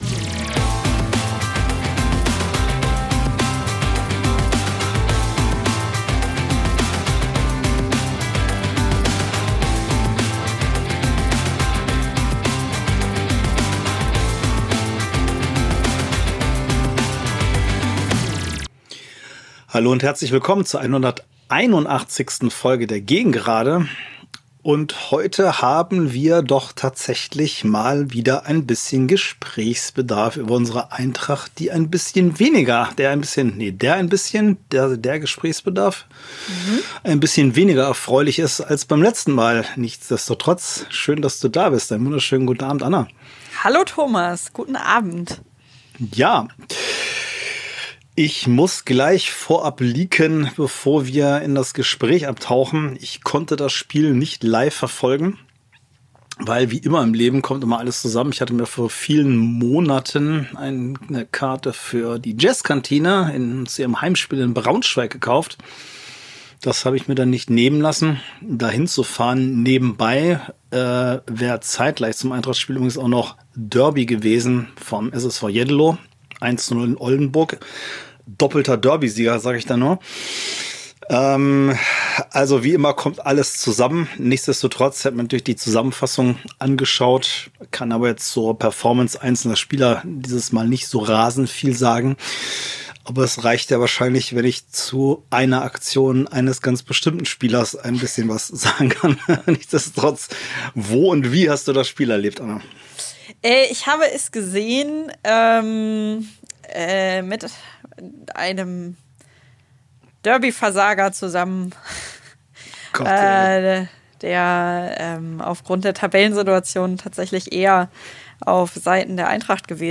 Und dieses Mal geht es in unserem Podcast tatsächlich verhältnismäßig hoch her - eine wunderbar kontroverse Diskussion!